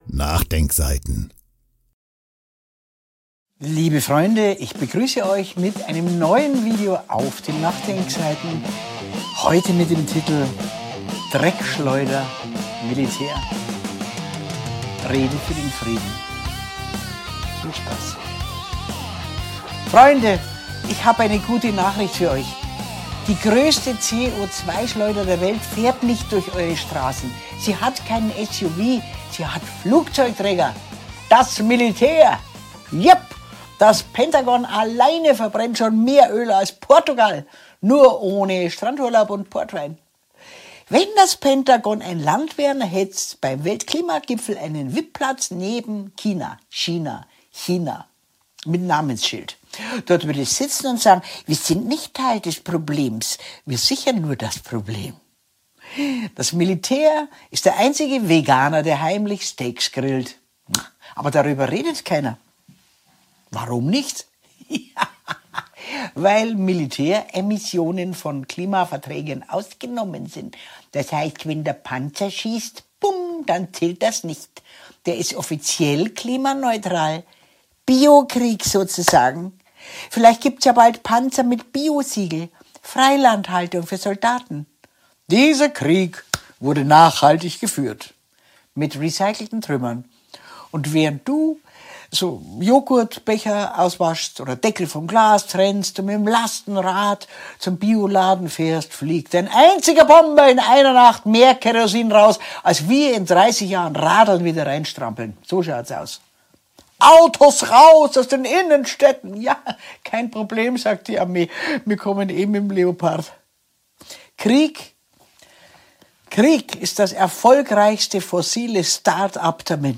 Lisa Fitz – Dreckschleuder Militär – Rede für den Frieden